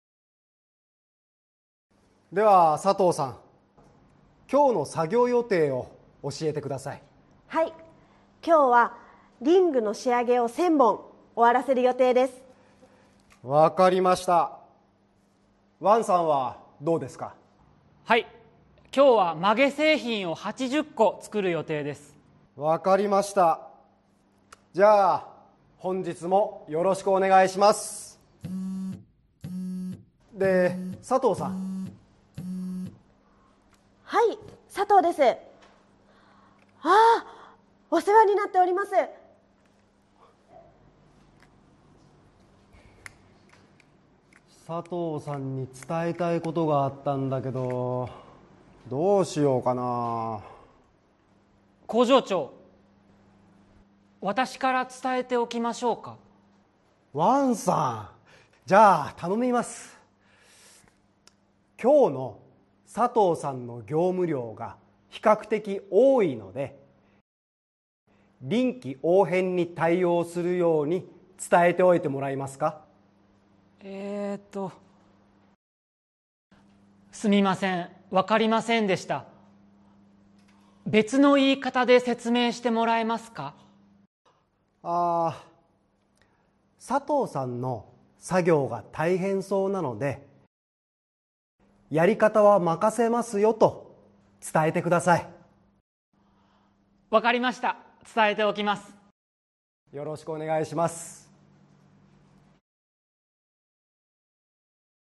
Role-play Setup
Conversation Transcript